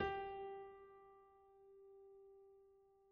/piano/G4.mp3